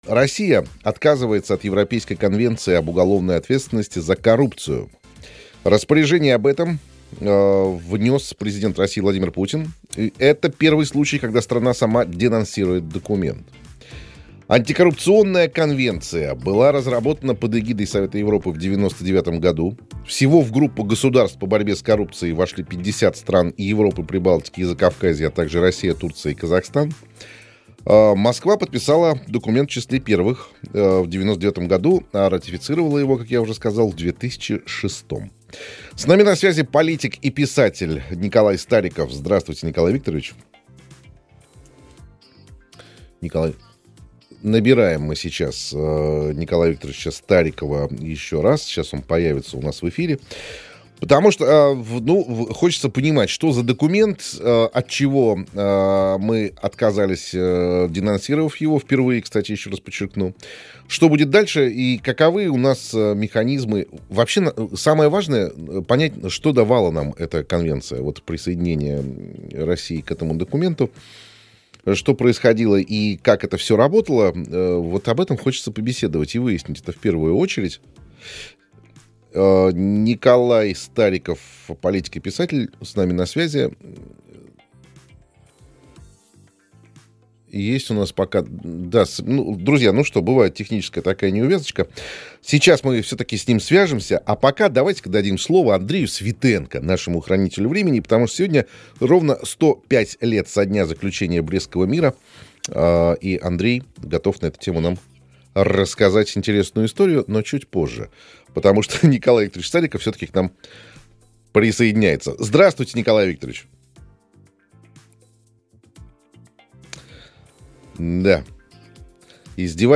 Рассказал о том, почему Россия отказалась от этой конвенции именно сейчас – в прямом эфире программы «Слушаем» на «Радио России».